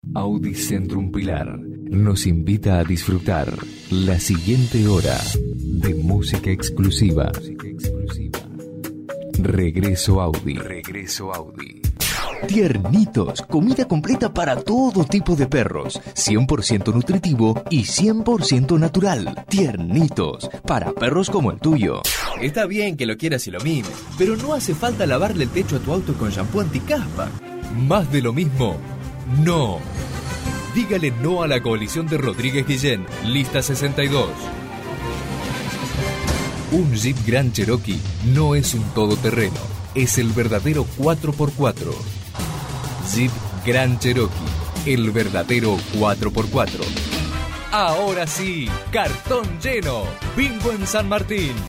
spanisch Südamerika
Sprechprobe: Sonstiges (Muttersprache):
voice over spanish.